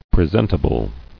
[pre·sent·a·ble]